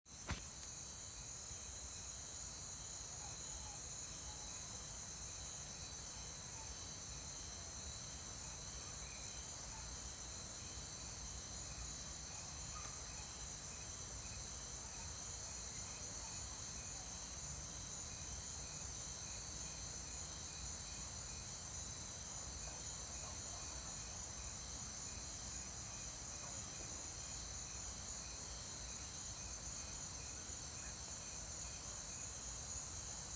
When the sun goes down at the farm in San Rafael, you have the songs of the crickets and the birds to serenade you to sleep.